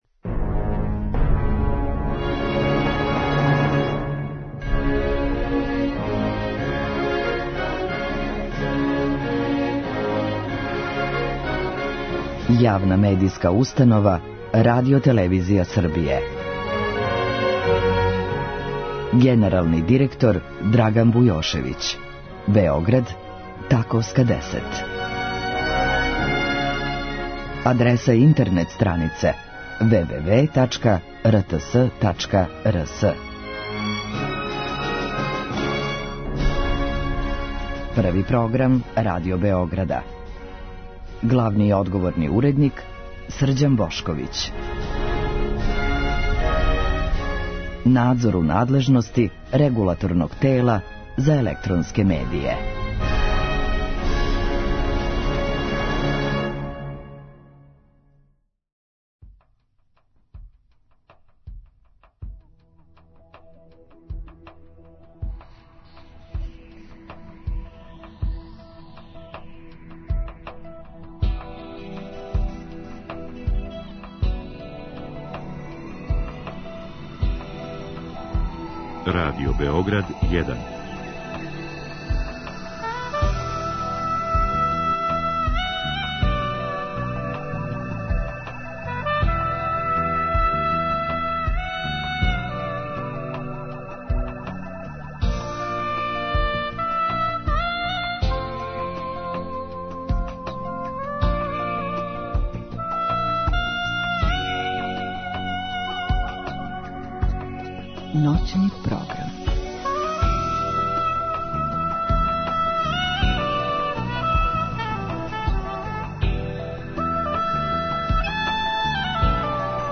Други сат предвиђен је за укључење слушалаца, који у програму могу поставити питање гошћи.